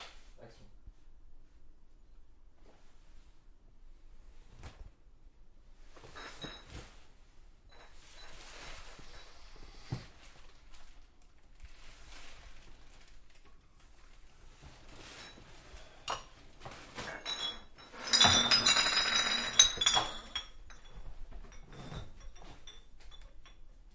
移动垃圾2
描述：一个g试图将垃圾扔进垃圾桶，然后无意中射入一堆玻璃瓶中。用ZOOM H6记录下来
Tag: 芯片 垃圾桶 的PERC 浮渣 食品 垃圾